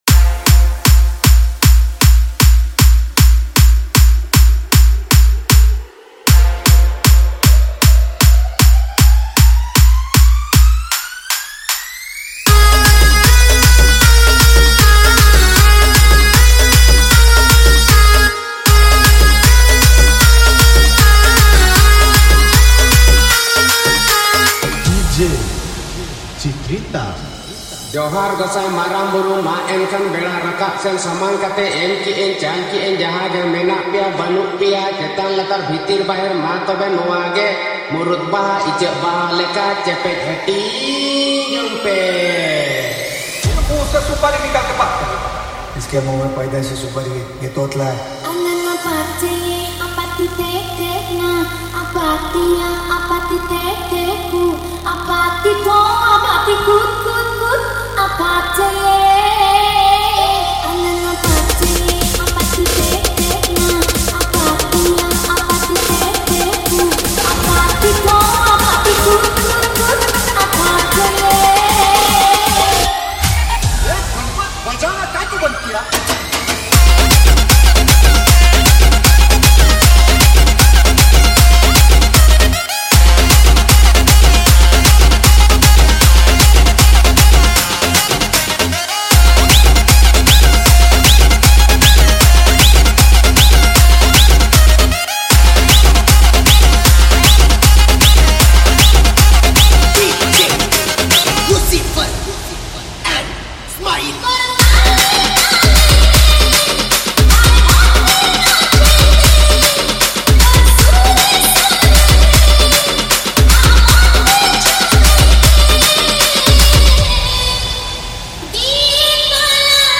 Single Dj Song Collection 2025 Songs Download